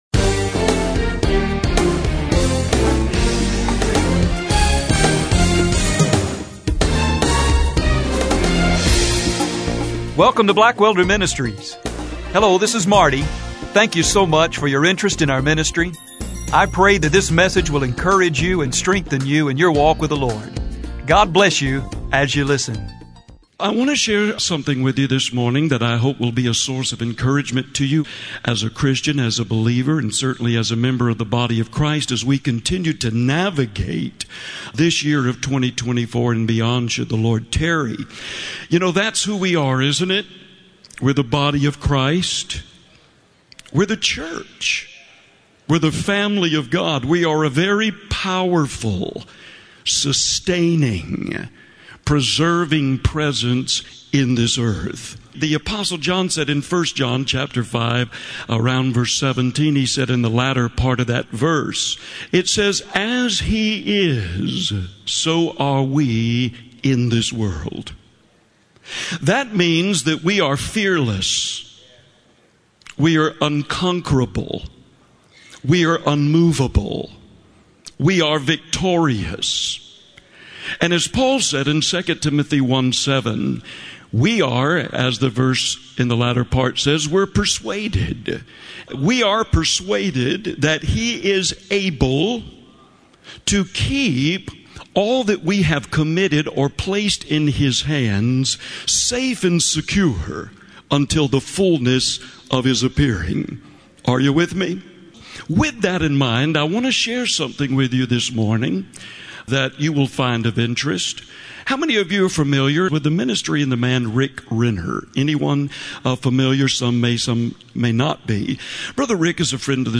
Please enjoy this encouraging message below entitled: “Have Faith in God”.